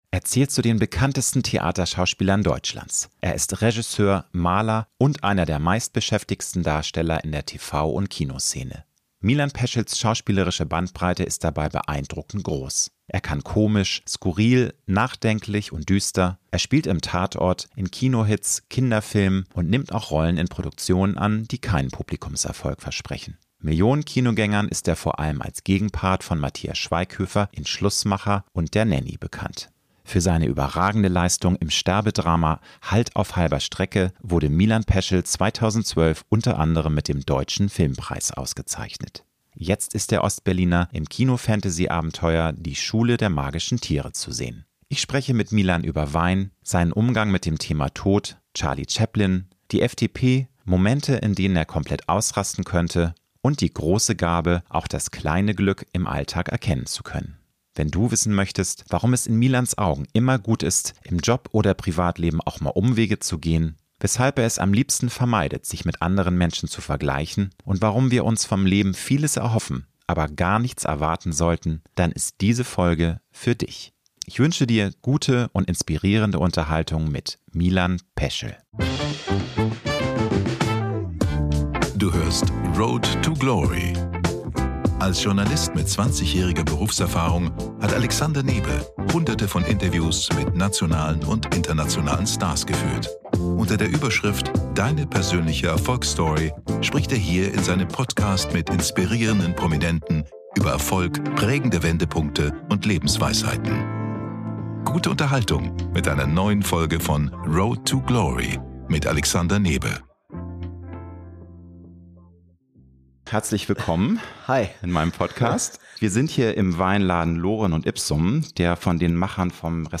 Ich spreche mit Milan über Wein, seinen Umgang mit dem Thema Tod, Charlie Chaplin, die FDP, Momente, in denen er komplett ausrasten könnte und die große Gabe, auch das kleine Glück im Alltag erkennen zu können.